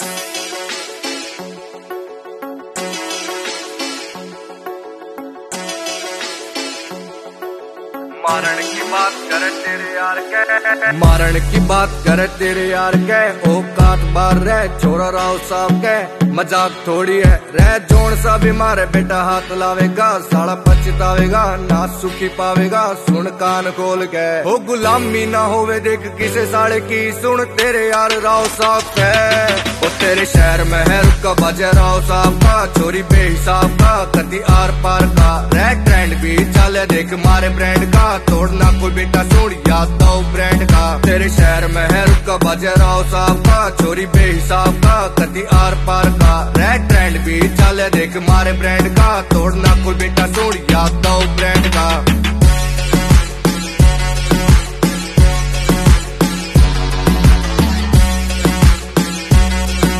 Slowed Reverb